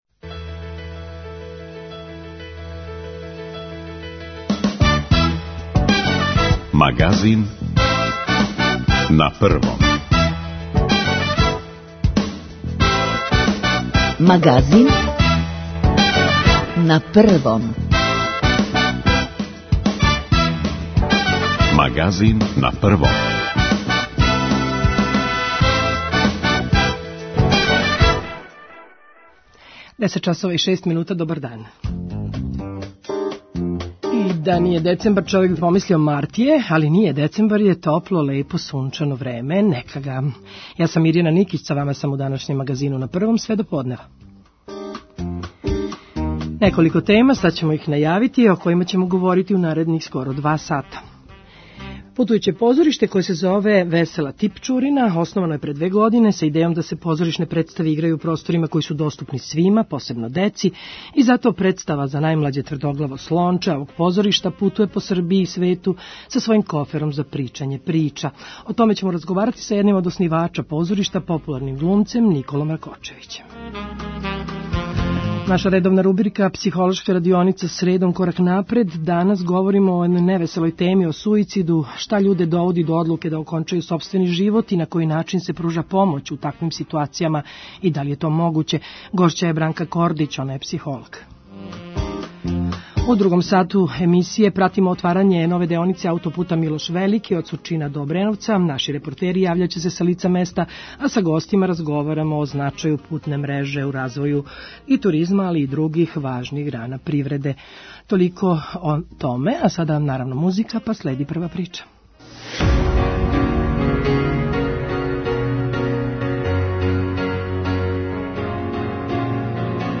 У другом делу емисије пратимо отварање деонице ауто-пута "Милош Велики", од Сурчина до Обреновца. Наши репортери ће се јављати са лица места, а са гостима разговорамо о значају путне мреже у развоју туризма и других, важних грана привреде.